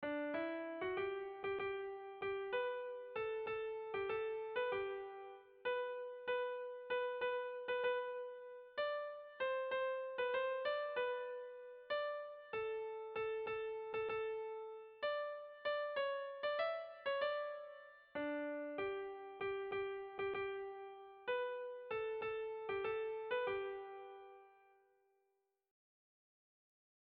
Irrizkoa
Oiartzun < Oarsoaldea < Gipuzkoa < Euskal Herria
Hamabiko handia (hg) / Sei puntuko handia (ip)
ABDE